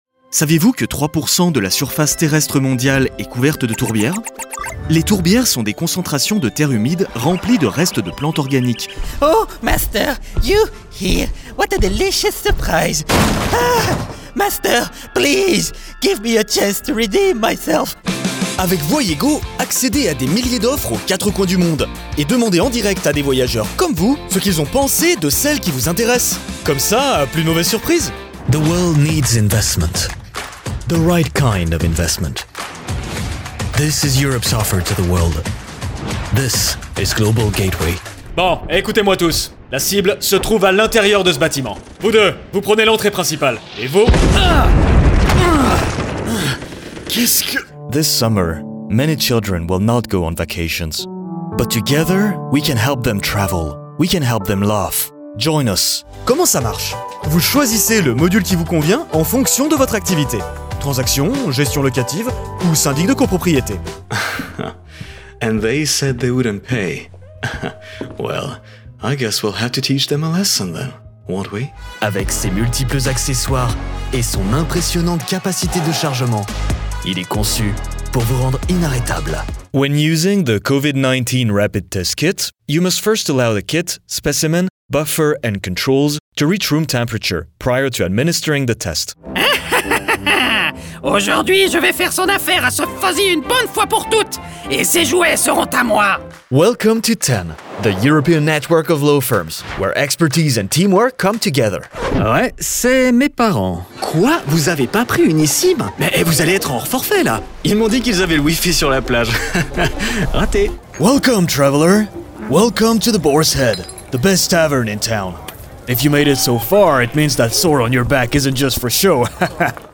Hire Smooth Voice Artists
Commercial Showreel FRENCH_01.mp3